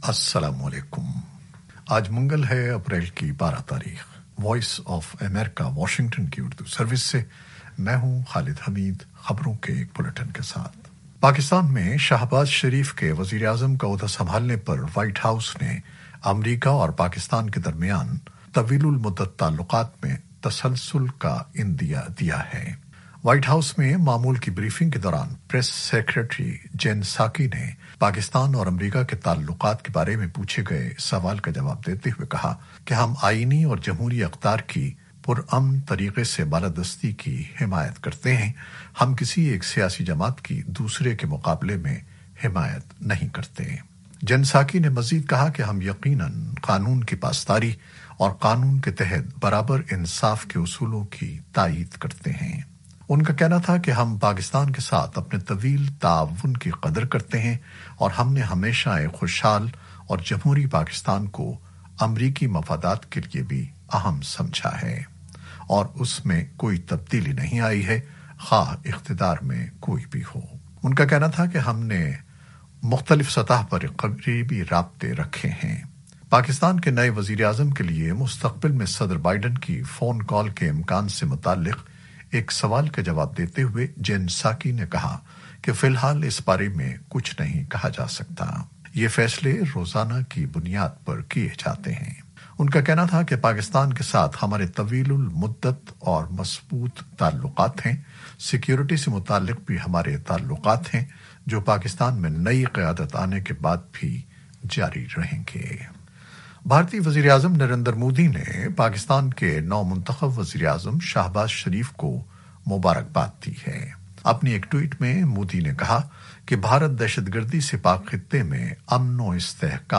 نیوز بلیٹن 2021-12-04